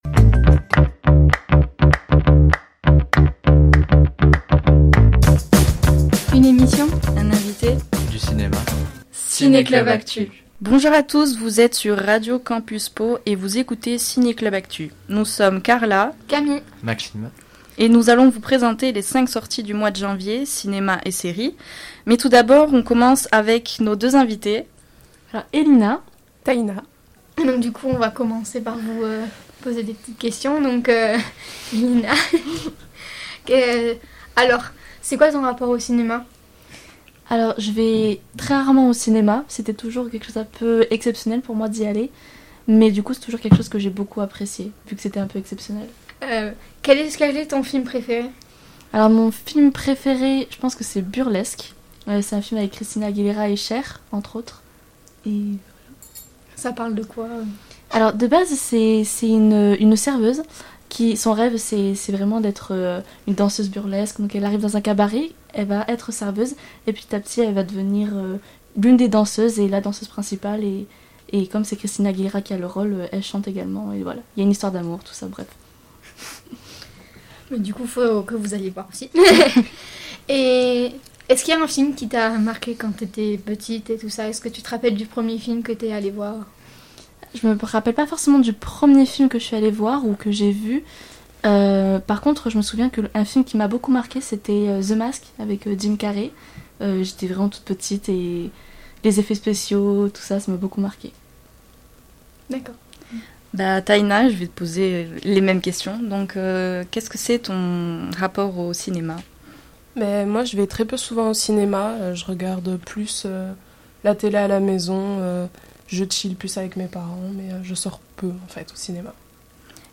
Il y a aussi une interview avec 2 invités, autour de leurs films préférés avant d'enchainer sur les sorties cinéma et séries du mois.